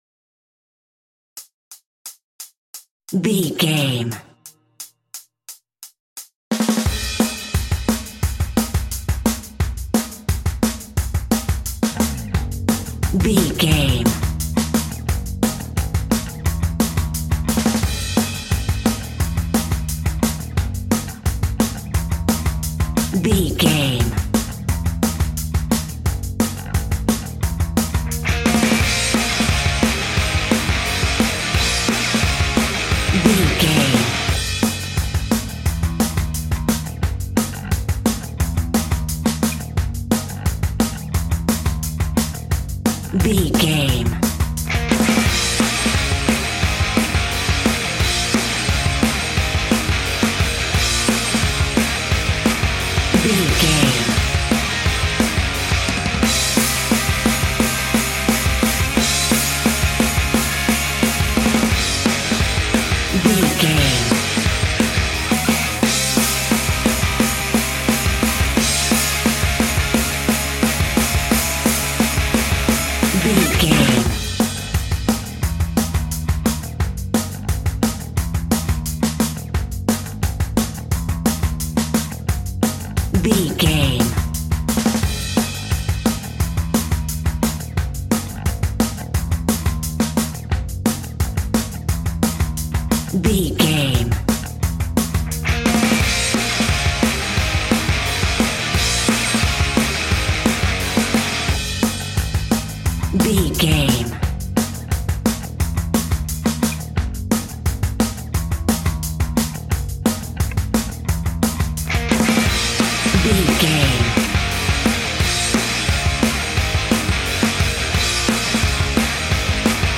Ionian/Major
E♭
Fast
energetic
driving
heavy
aggressive
electric guitar
bass guitar
drums
metal
hard rock
heavy metal
rock instrumentals